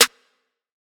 MZ Snare [Metro Accent Hi].wav